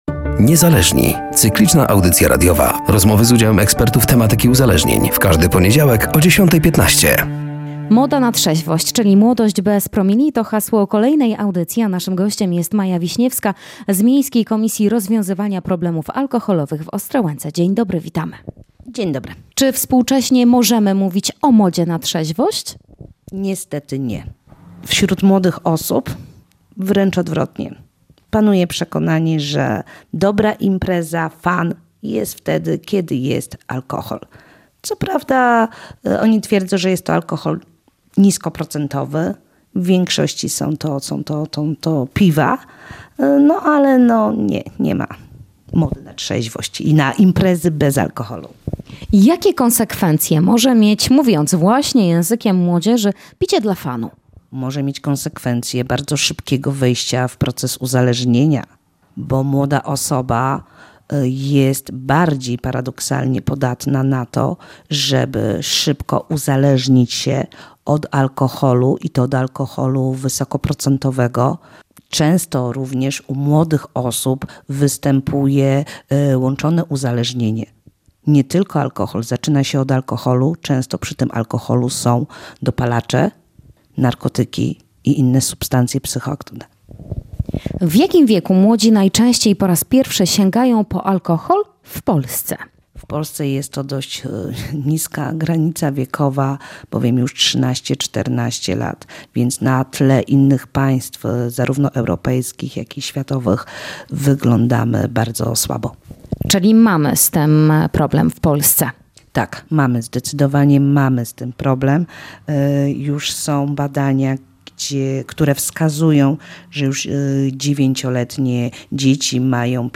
“Niezależni” cykliczna audycja radiowa